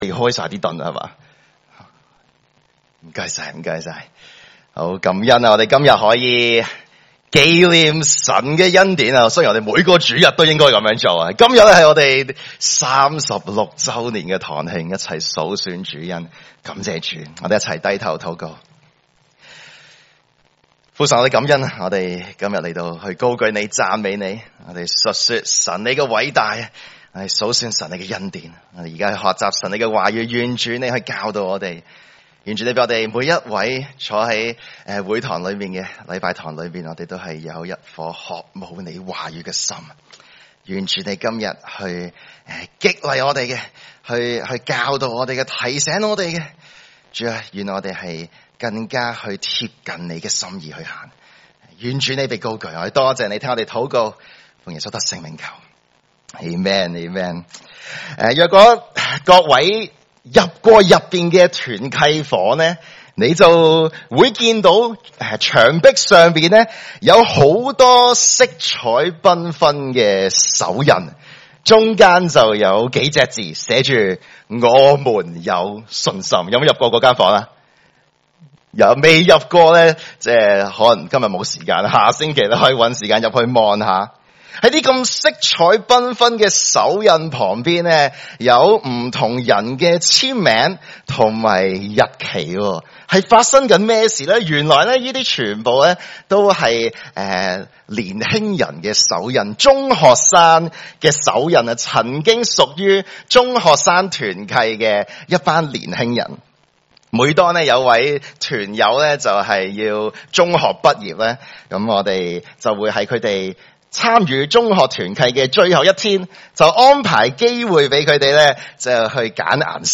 主日崇拜證道系列
來自講道系列 "解經式講道"